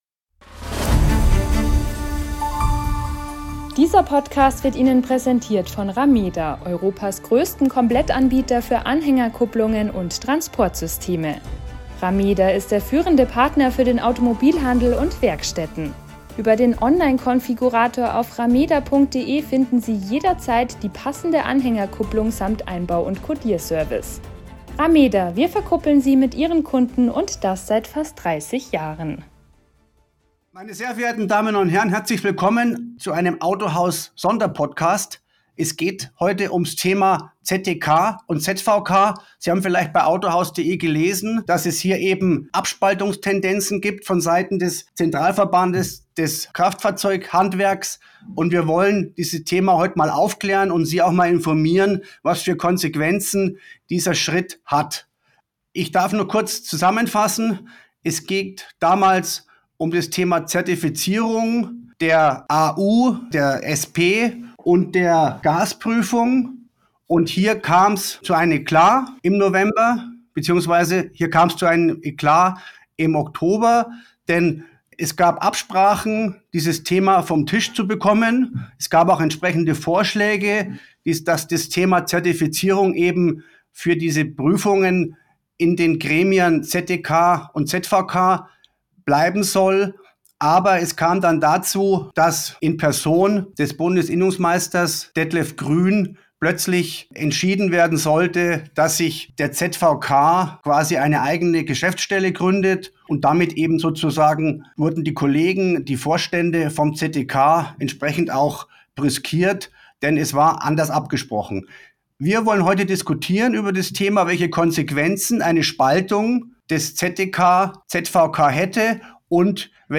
In einer exklusiven Sonderfolge sprechen zahlreiche Branchengrößen über die inszenierte Trennung von ZDK und ZVK. Was dahintersteckt und wie es weitergehen könnte – jetzt im AUTOHAUS Podcast.